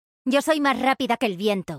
ES JETT From Valorant Random Voice Lines